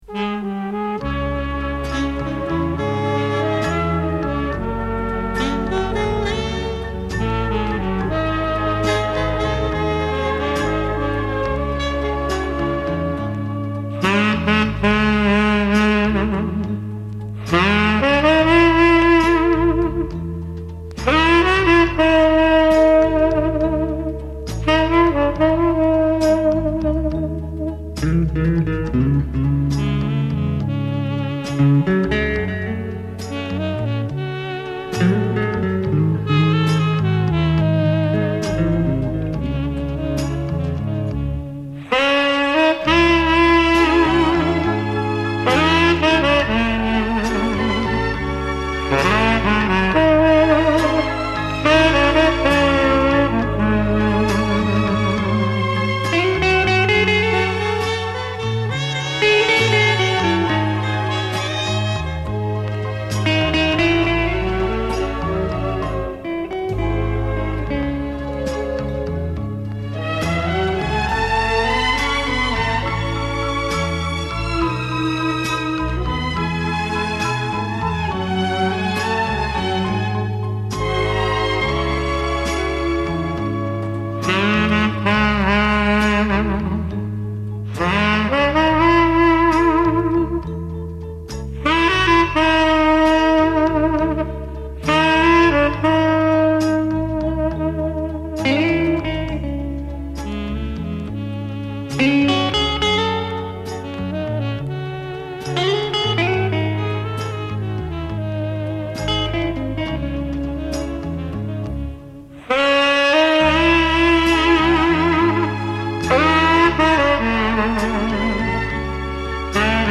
блюз (открыта)